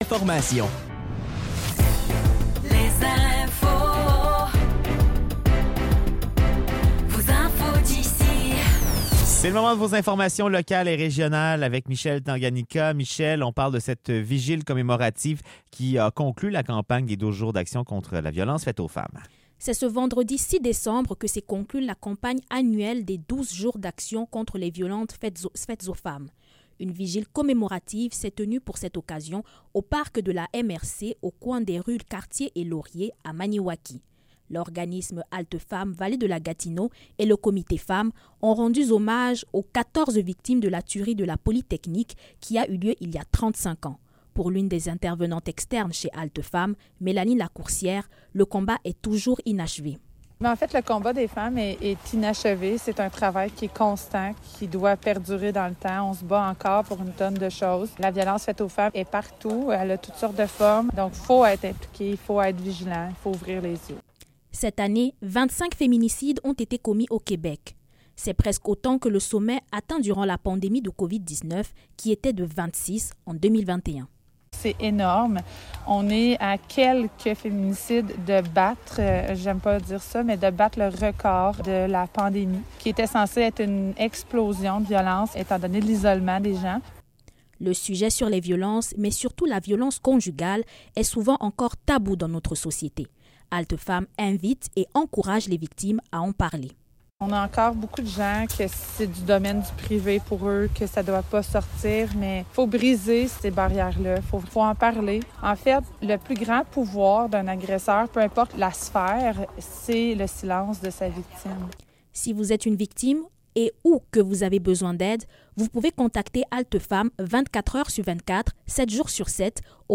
Nouvelles locales - 6 décembre 2024 - 16 h